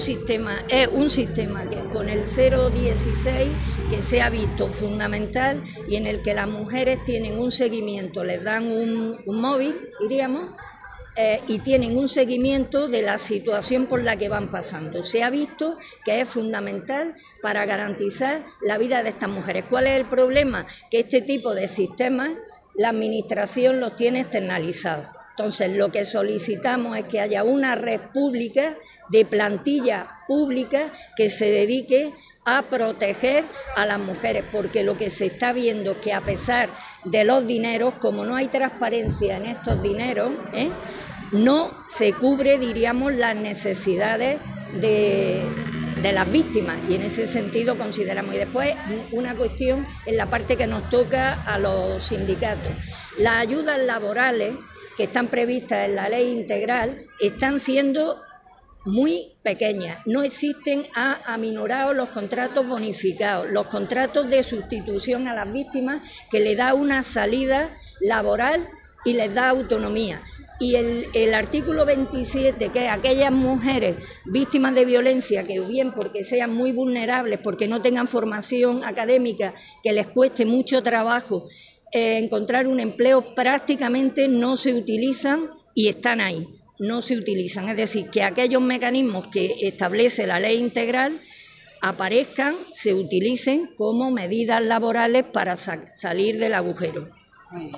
Declaraciones-25N.mp3